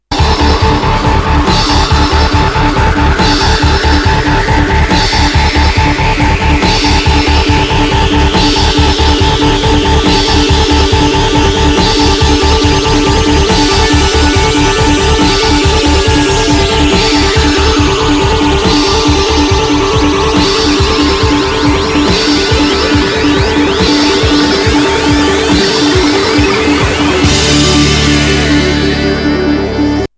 Breakbeat / Progressive House / Techno Lp Reissue